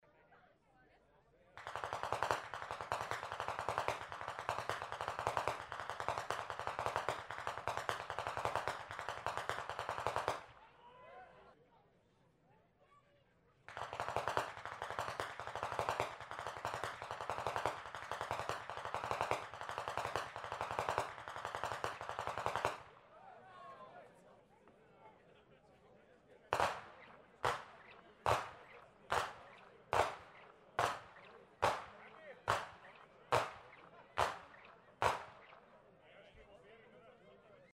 50. Ainringer Gemeindepreisschnalzen in Perach am 02.02.2025